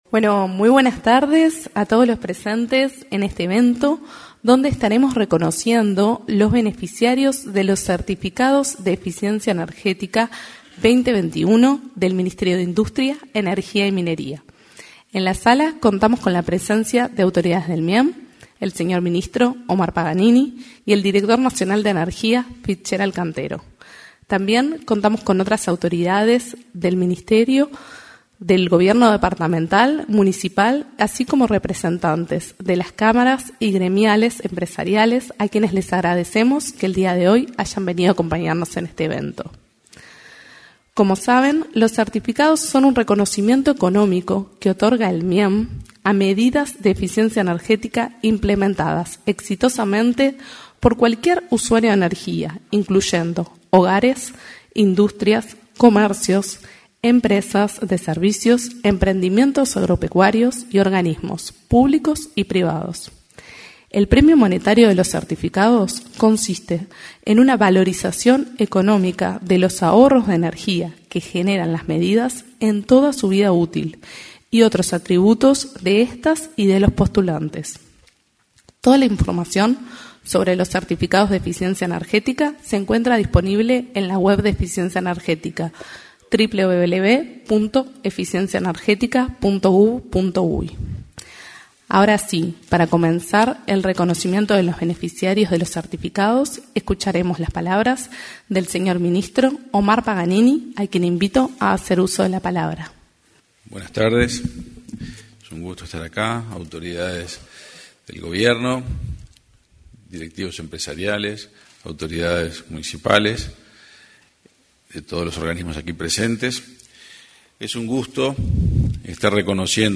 En la oportunidad, se expresaron el ministro de Industria, Energía y Minería, Omar Paganini, y el director nacional de Energía, Fitzgerald Cantero.